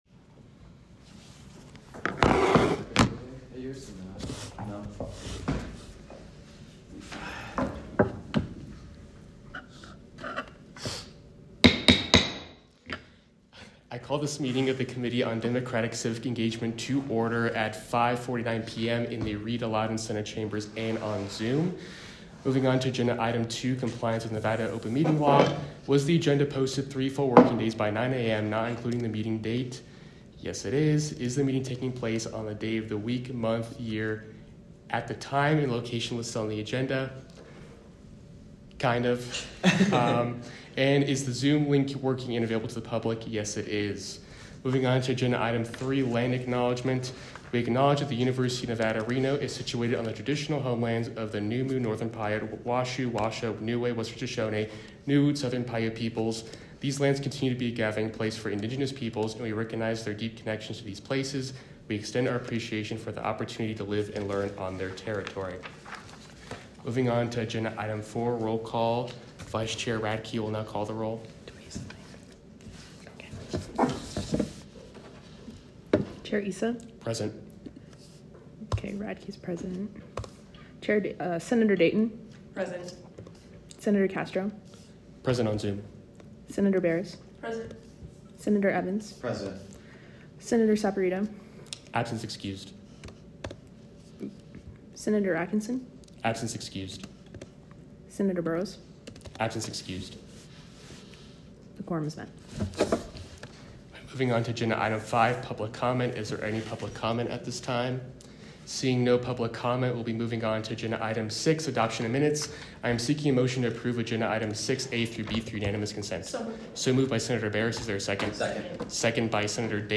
Location : Rita Laden Senate Chambers
Audio Minutes